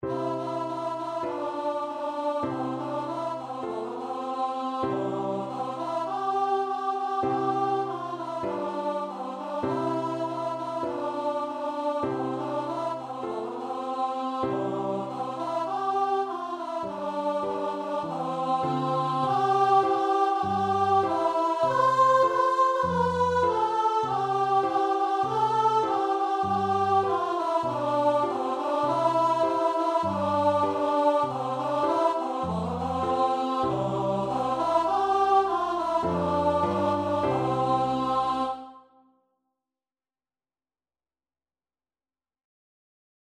Voice
Traditional Music of unknown author.
C major (Sounding Pitch) (View more C major Music for Voice )
4/4 (View more 4/4 Music)
Moderato
G4-C6